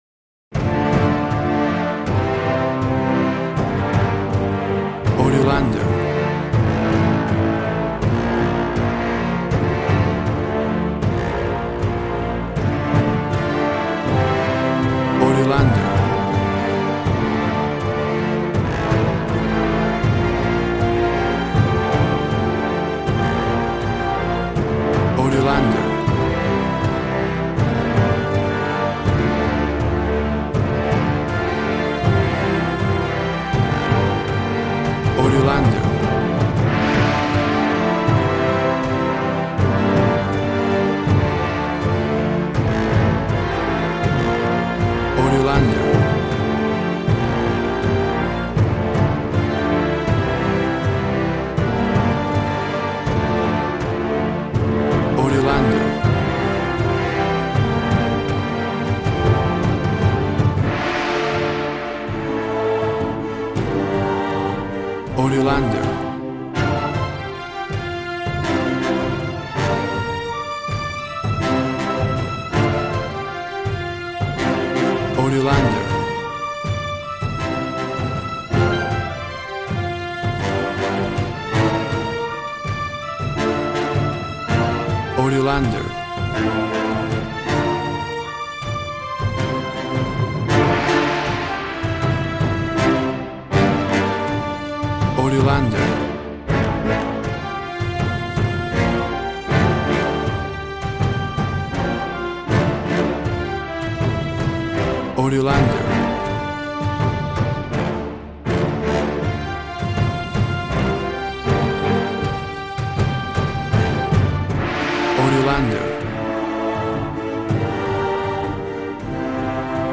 Epic and glory music.
Tempo (BPM) 80